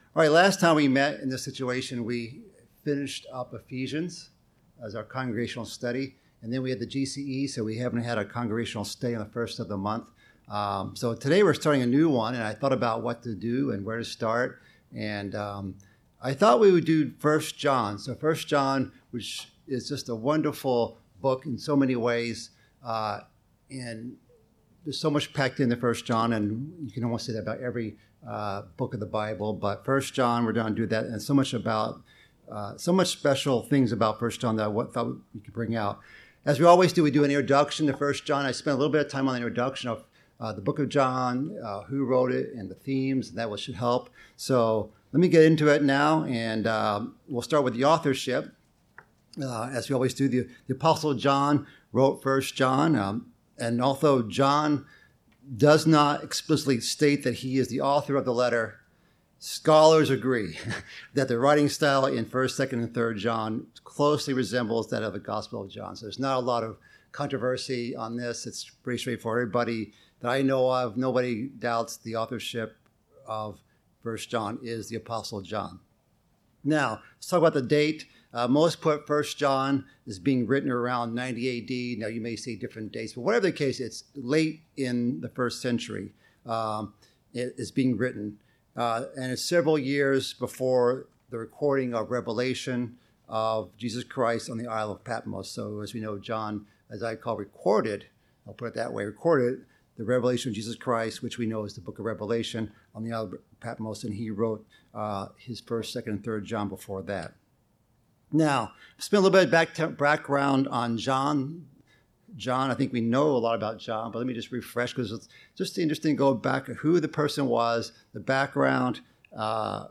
We begin our monthly congregational Bible study into the first epistle of John with this message. This includes an introduction and summary of the life of John, as well as covering the first two verses of the first chapter.